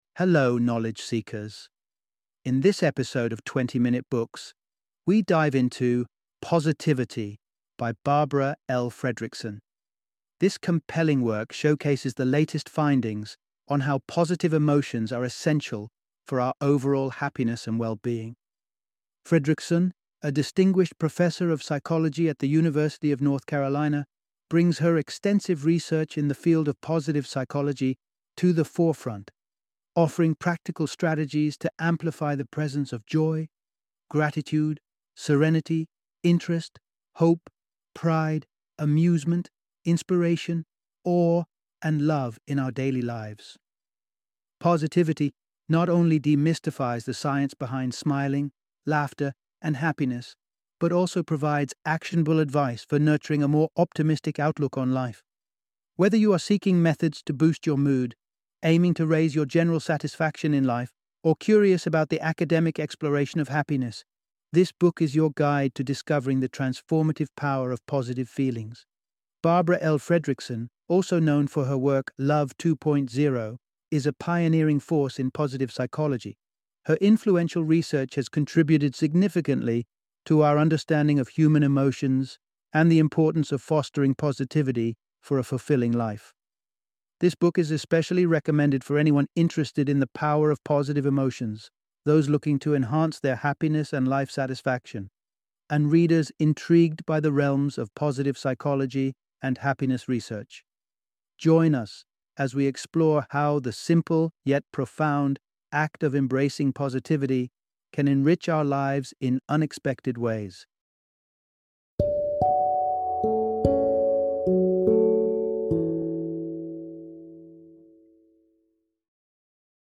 Positivity - Audiobook Summary